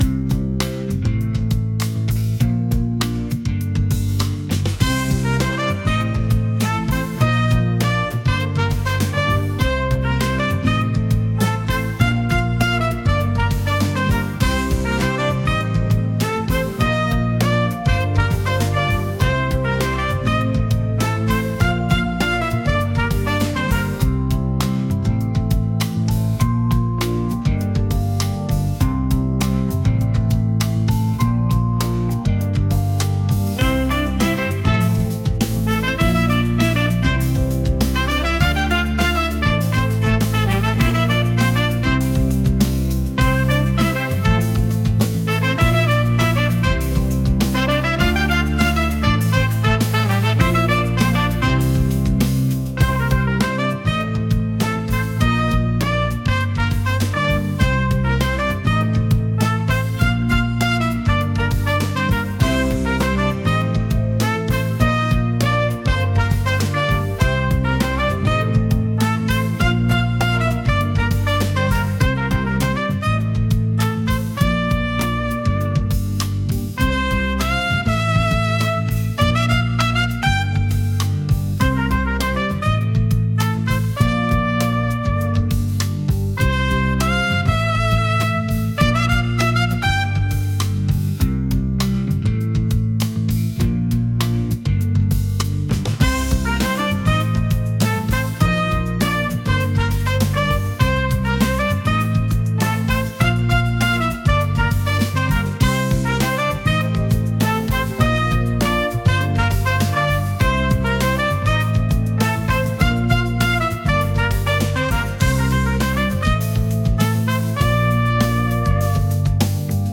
「幻想的」